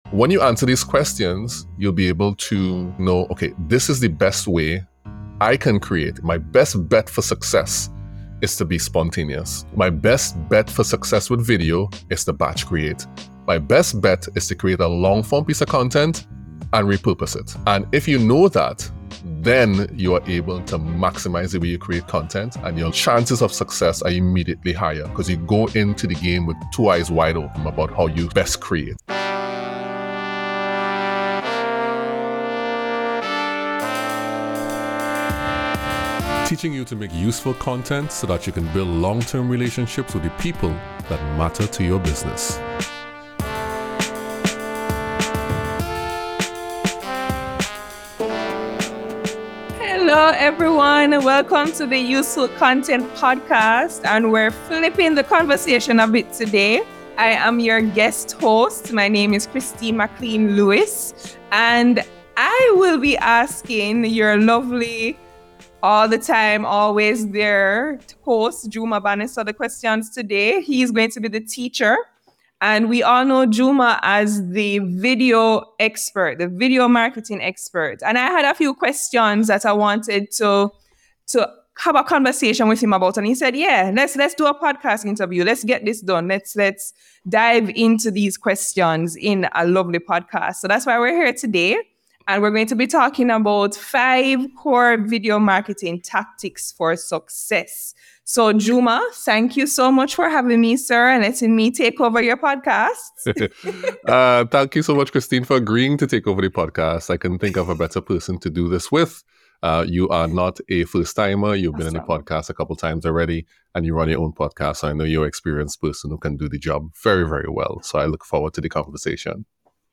In this episode of the Useful Content Podcast, guest host
interviews